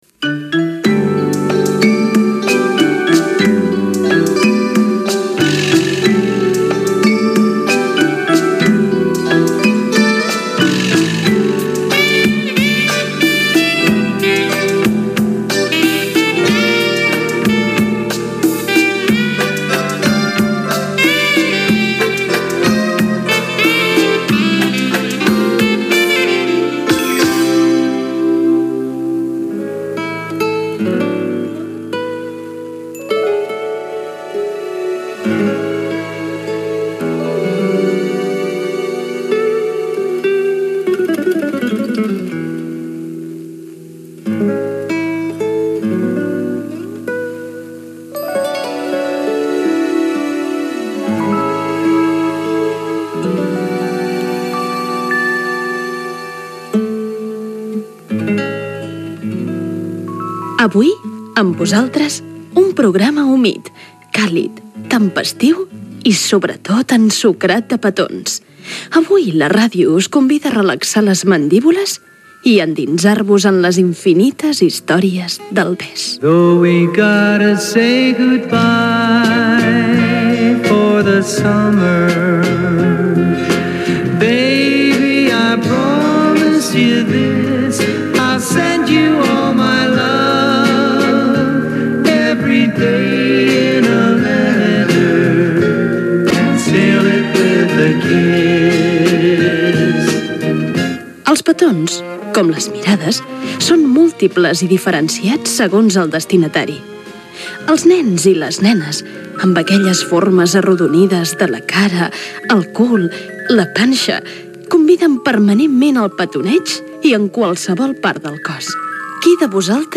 Indicatiu de la ràdio
Gènere radiofònic Entreteniment